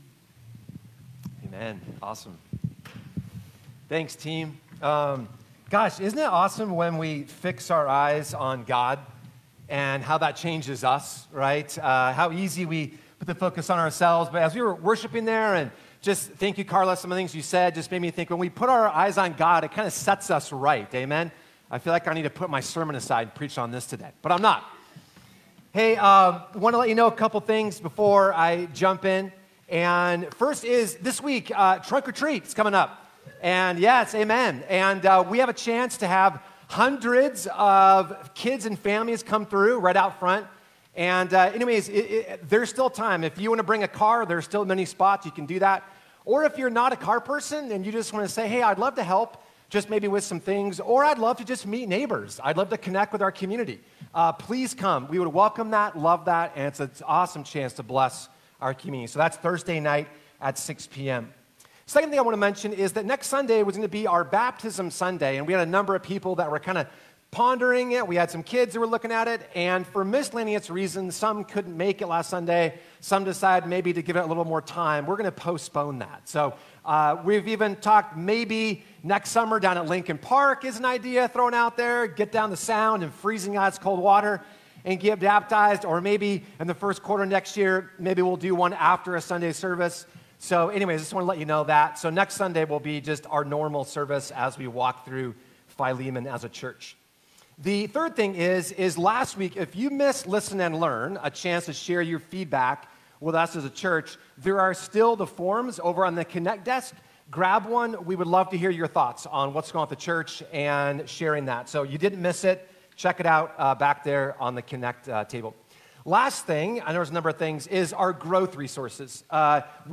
Sermons | Arbor Heights Community Church